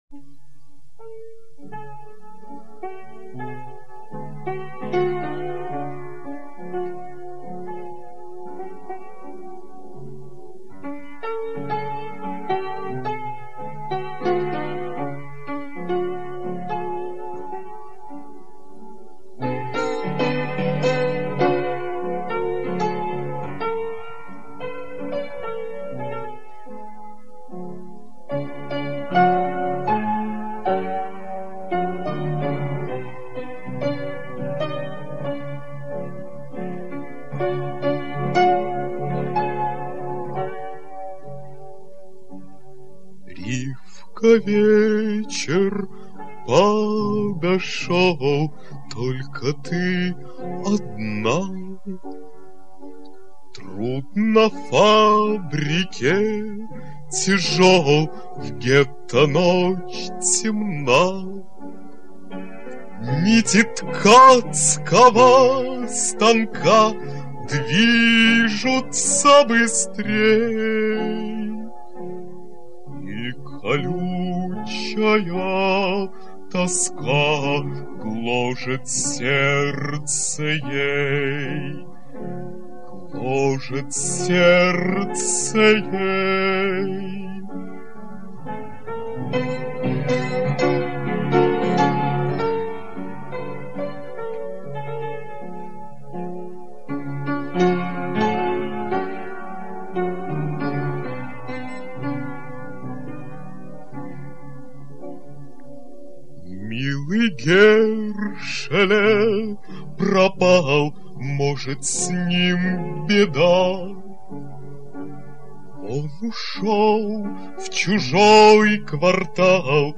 под собственный фортепианный аккомпанемент.
в собственном сопровождении на фортепиано
Записи из частного звукового архива.